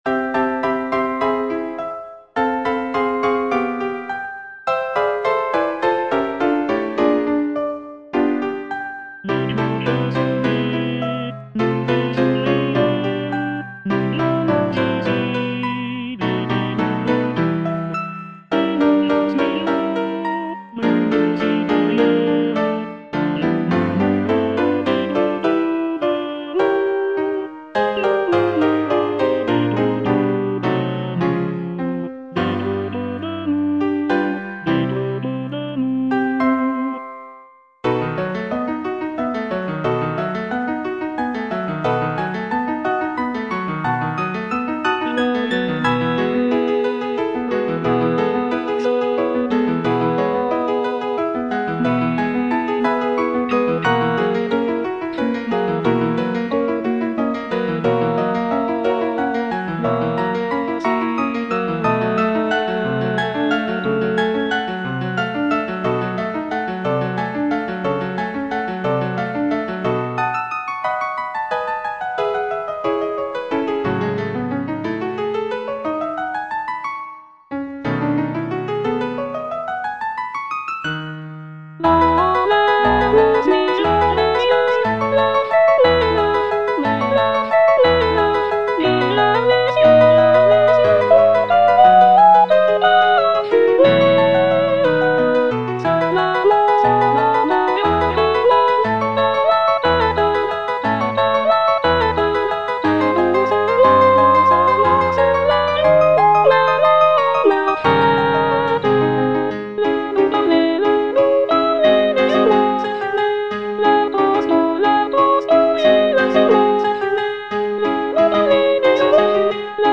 G. BIZET - CHOIRS FROM "CARMEN" Chorus of cigarette-girls (soprano I) (Emphasised voice and other voices) Ads stop: auto-stop